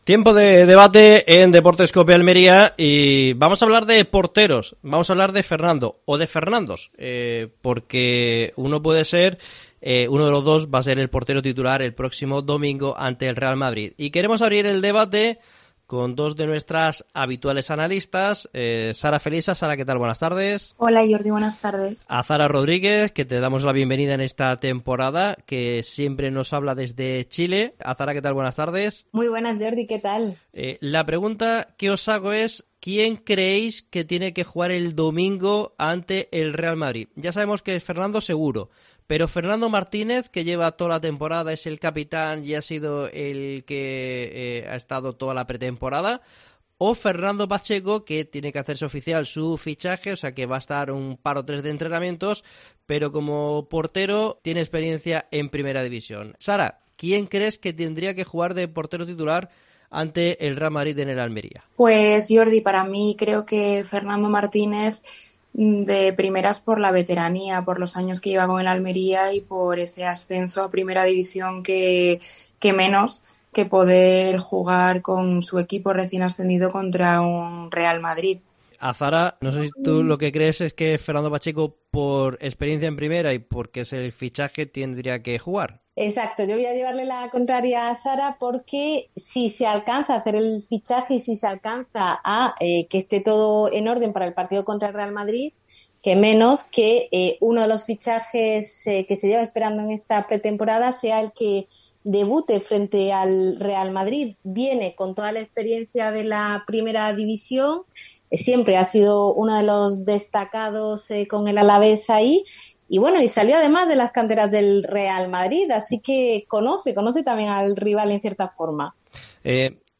Debate Deportes COPE Almería. ¿Fernando Martínez o Fernando Pacheco ante el Madrid?